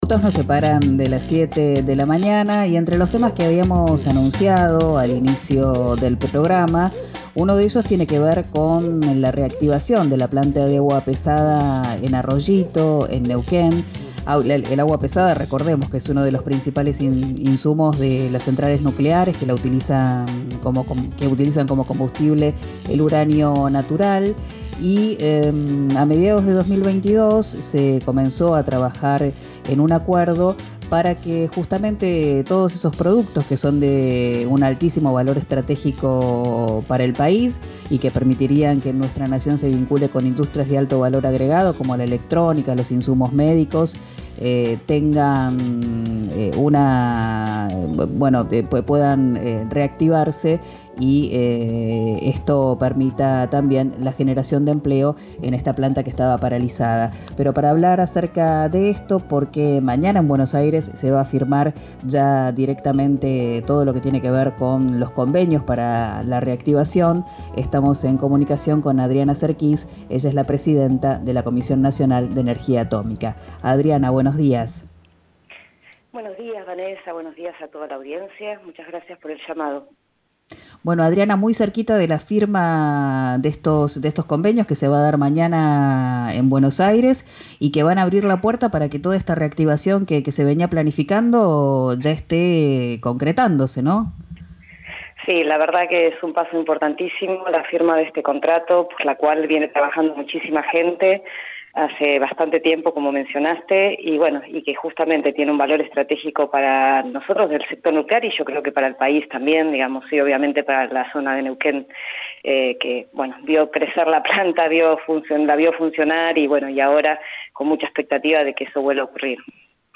La presidenta de la Comisión Nacional de Energía Atómica (CNEA), Adriana Serquis, detalló a RIO NEGRO RADIO los puntos principales del acuerdo.